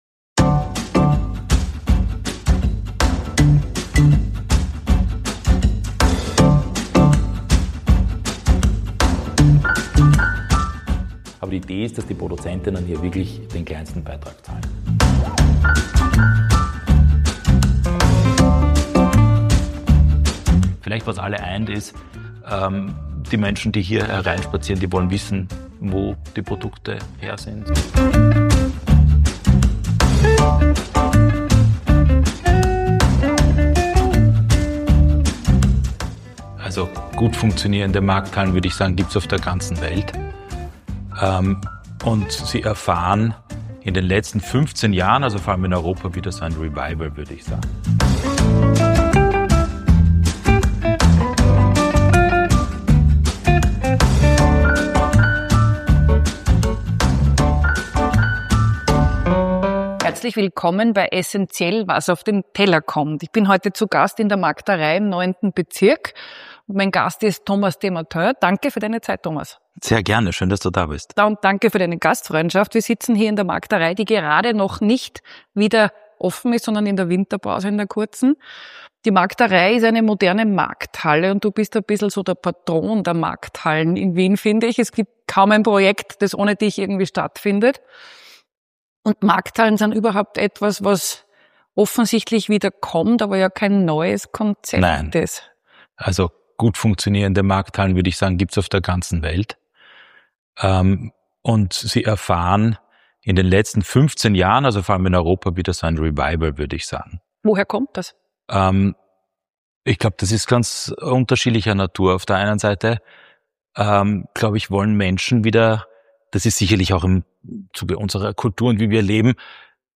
Ein Gespräch über Erlebnis, Direktvermarktung und die neue Rolle der Nahversorger:innen.